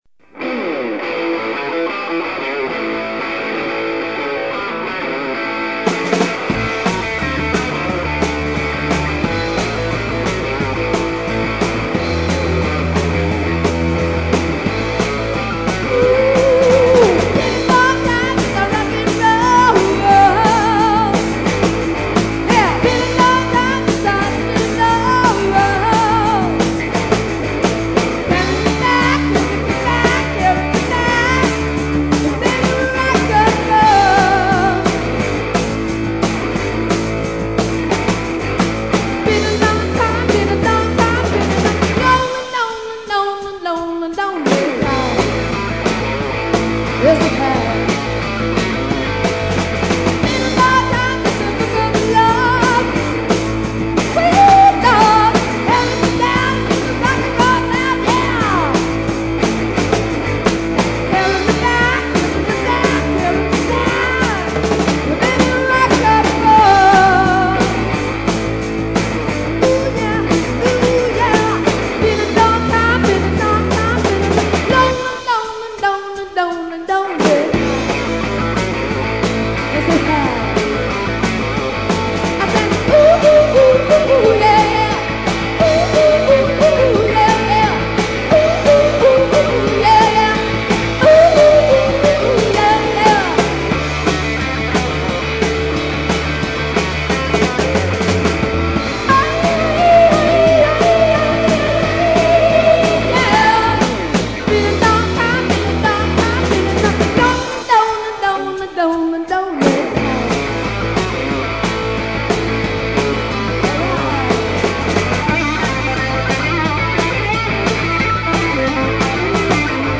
ROCK 'N ROLL
Live At L. C. Saloon 1/16/83 + Bonus Tracks